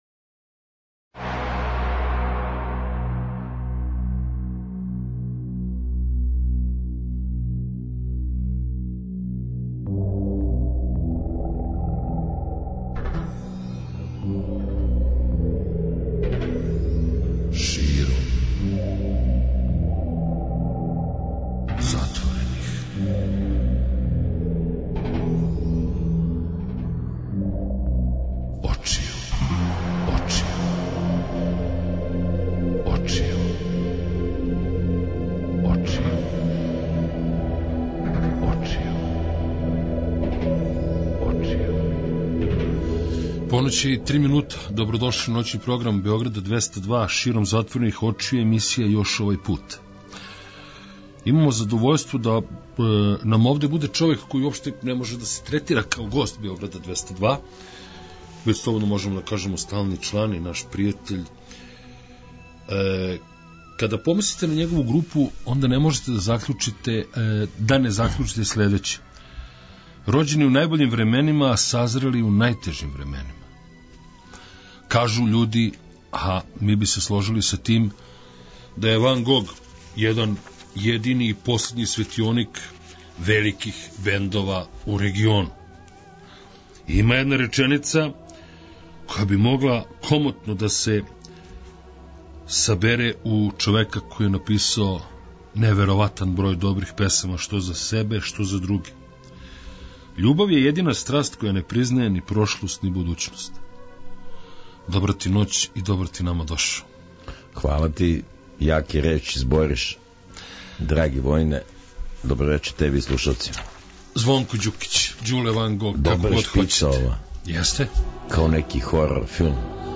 Ноћас нам у госте долази фронтмен групе Ван Гог, Звонимир Ђукић Ђуле.